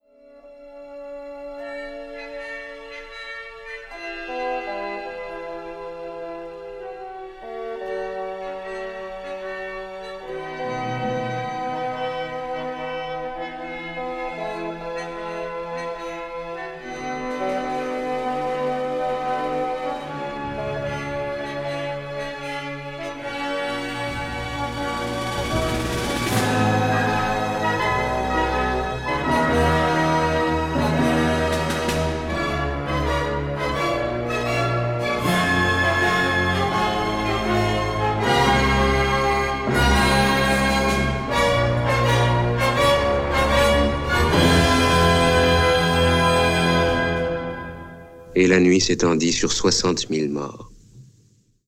Narrateur : Cosette chez les Thénardiers